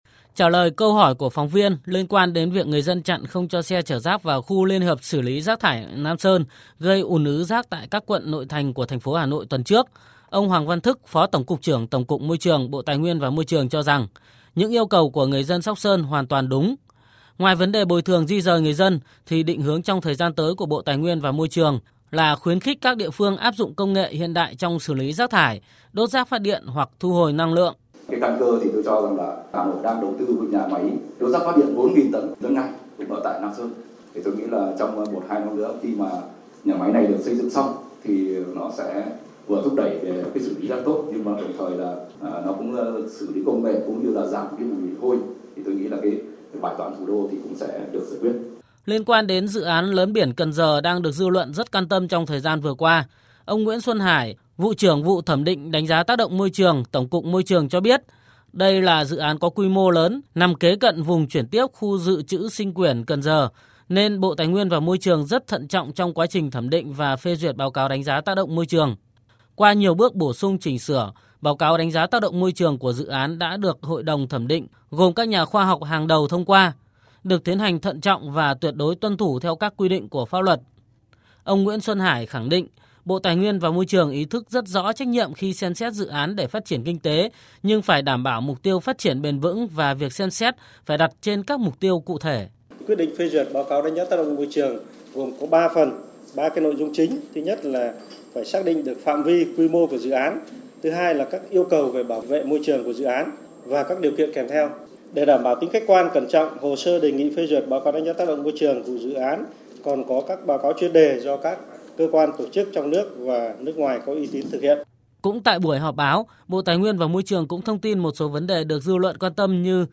VOV1 - Sáng nay (20/07), tại Hà Nội, Bộ Tài nguyên và Môi trường tổ chức họp báo thường kỳ Quý II năm 2020. Trong đó 2 vấn nóng đang thu hút được sự quan tâm của dư luận là câu chuyện xử lý rác thải của thành phố Hà Nội và báo cáo đánh giá tác động môi trường dự án lấn biển Cần Giờ.